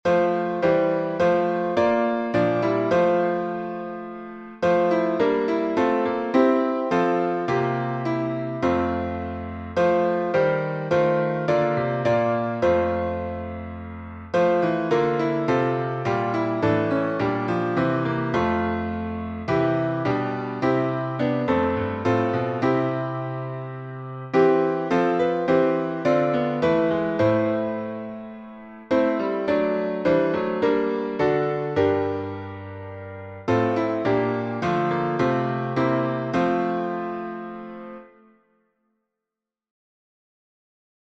#6125: Now Thank We All Our God — alternate harmonies | Mobile Hymns
Key signature: F major (1 flat) Time signature: 4/4
Now_Thank_We_All_Our_God_altharmony.mp3